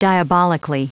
Prononciation du mot diabolically en anglais (fichier audio)
Prononciation du mot : diabolically